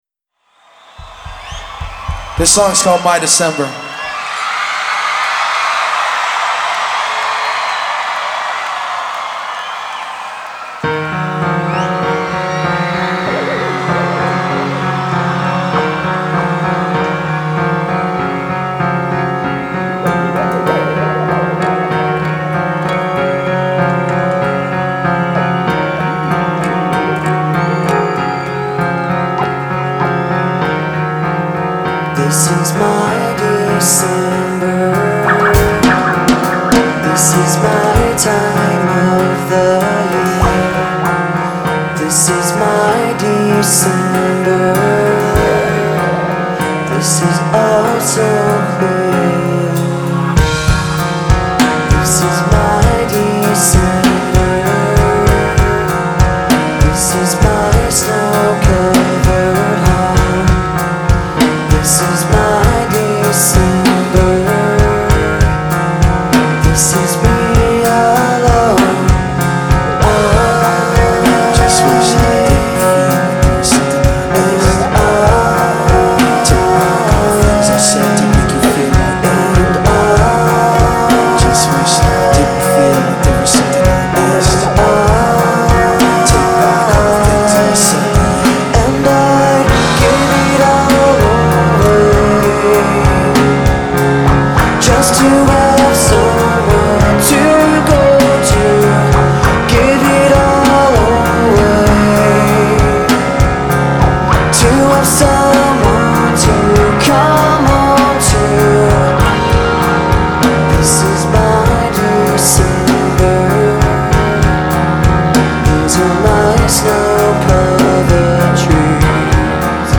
Genre : Rock, Alternative Rock